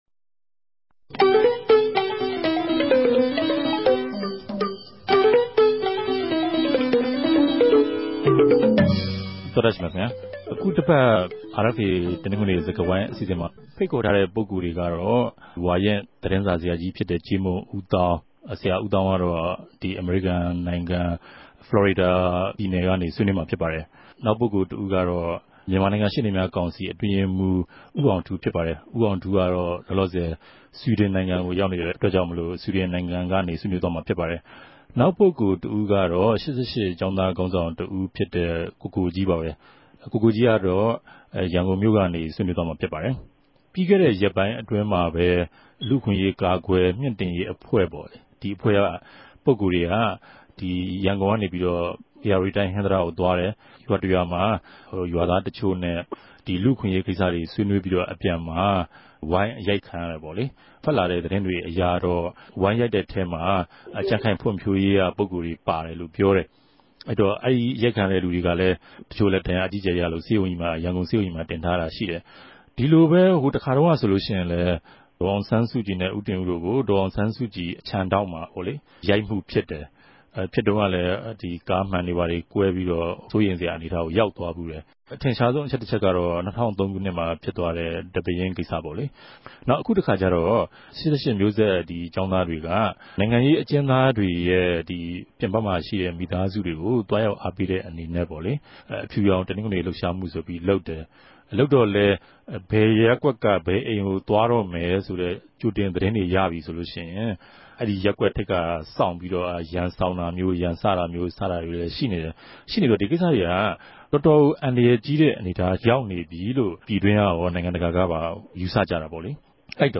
တနဂဿေိံြ ဆြေးေိံြးပြဲစကားဝိုင်း